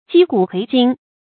稽古揆今 jī gǔ kuí jīn
稽古揆今发音